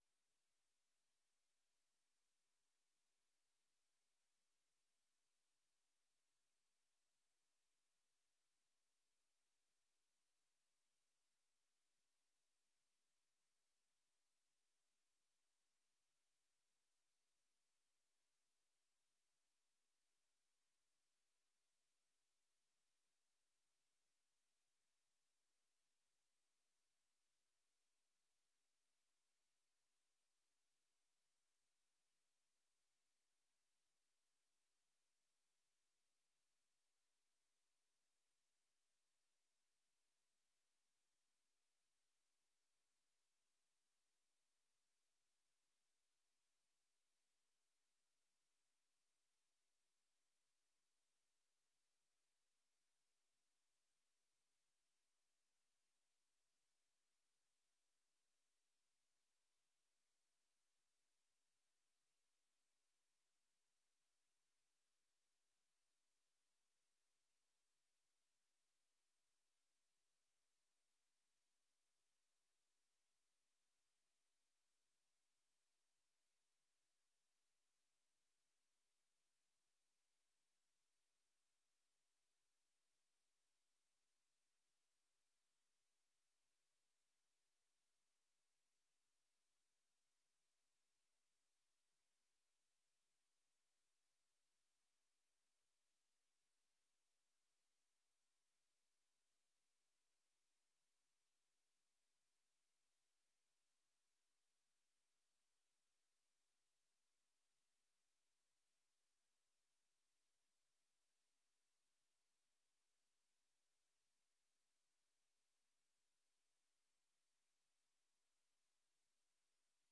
Dit is een uitloopvergadering van de raadsvergadering op 28 mei 2024.